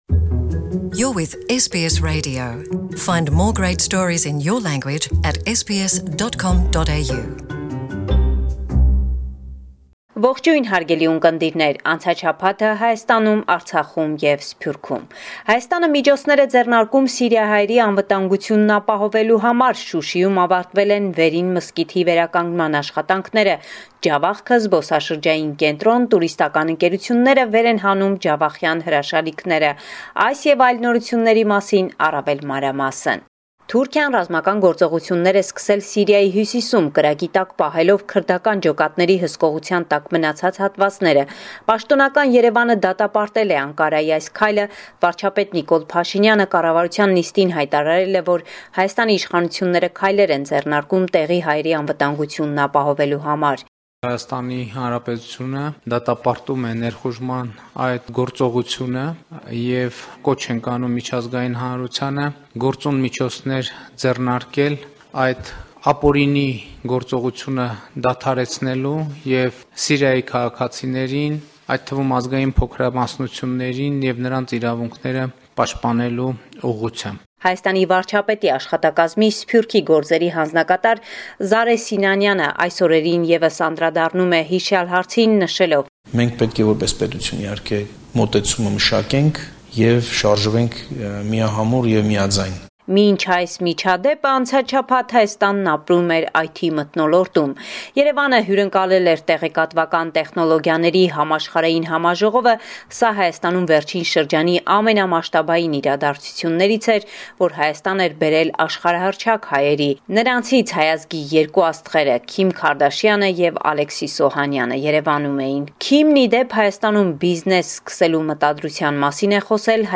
Latest News from Armenia– October 15, 2019